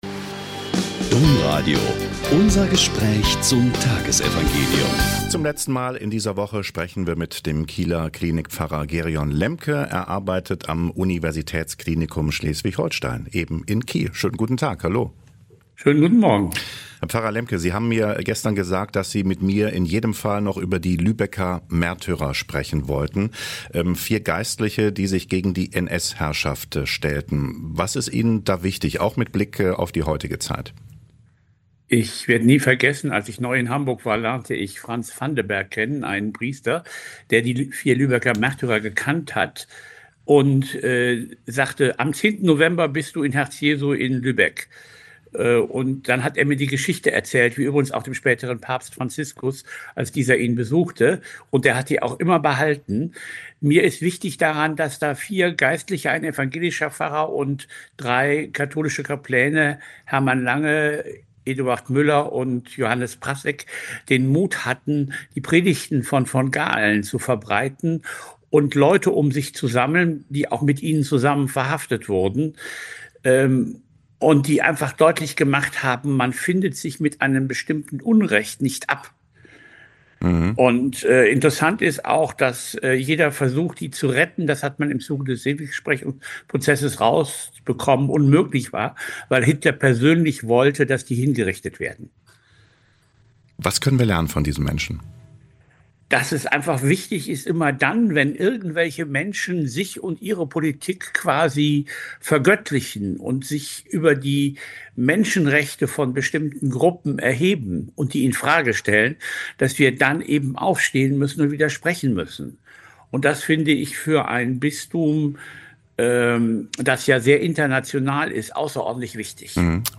Joh 14,7-14 - Gespräch